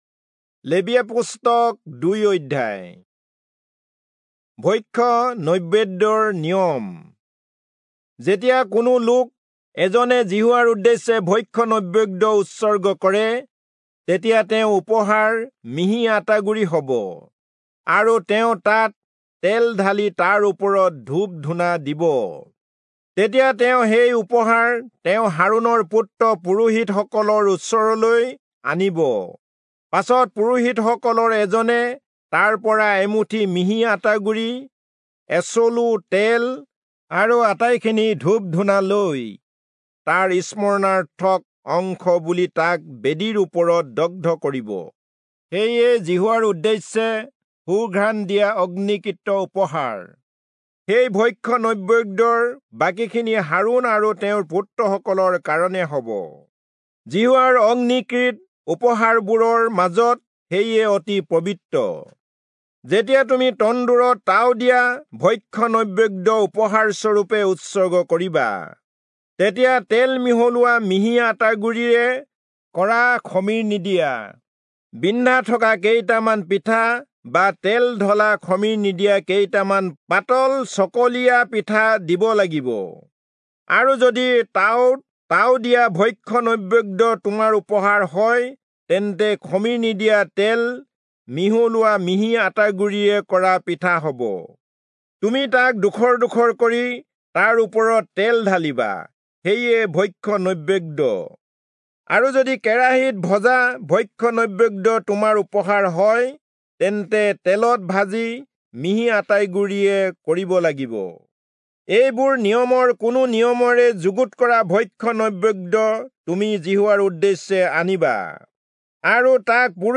Assamese Audio Bible - Leviticus 2 in Irvmr bible version